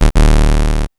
laser_02.wav